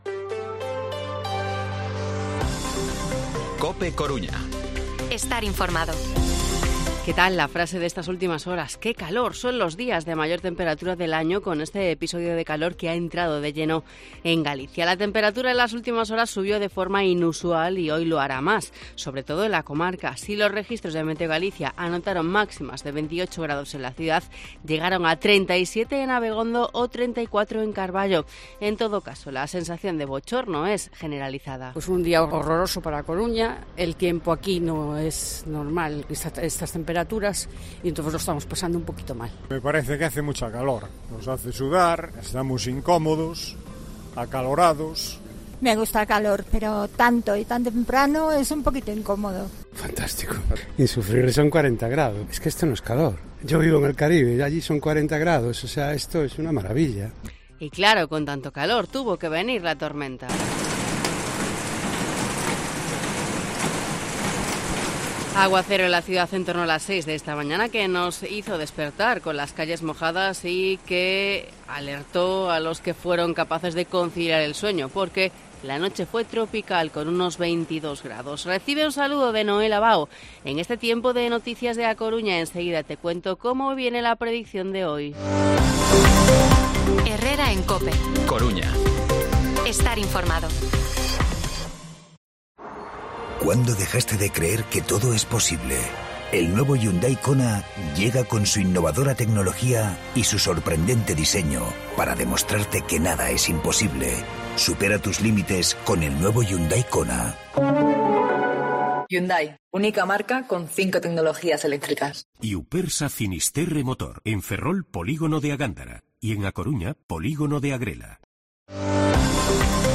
Informativo Herrera en COPE Coruña miércoles, 23 de agosto de 2023 8:24-8:29